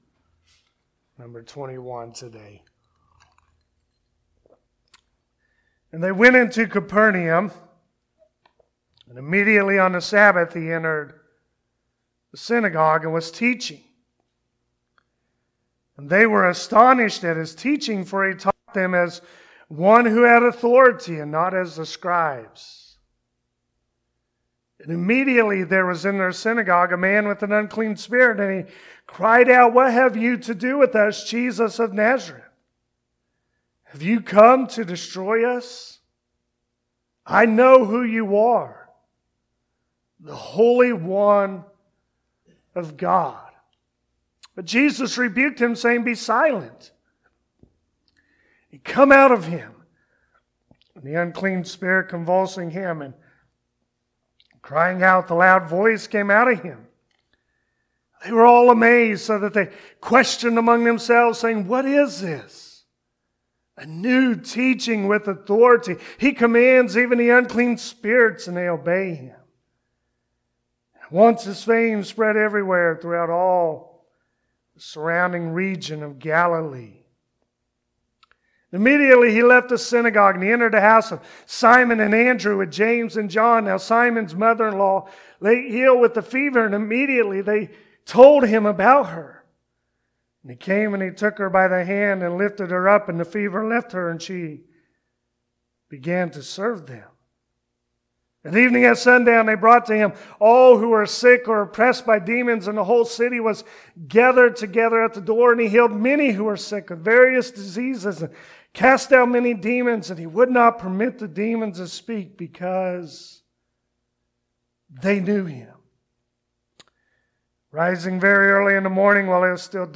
Mark 1:21-45 Service Type: Sunday Morning Mark 1:21-45.